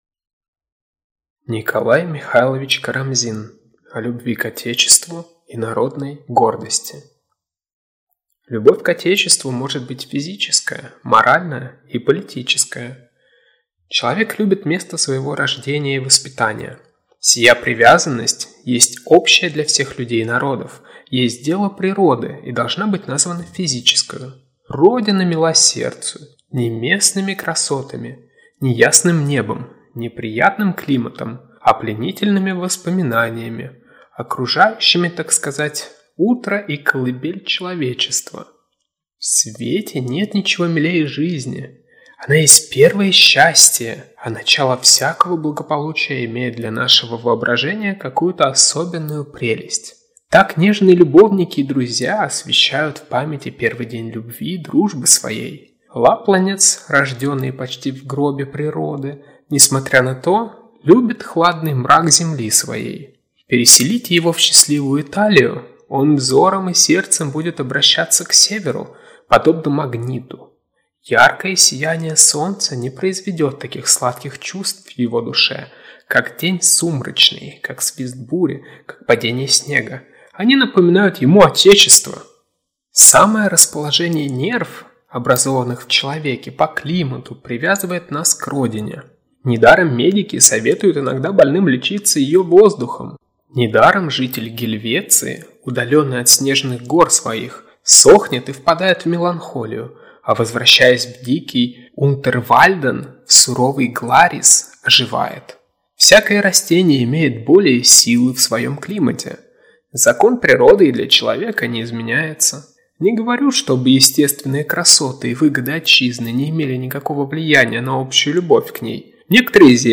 Аудиокнига О любви к отечеству и народной гордости | Библиотека аудиокниг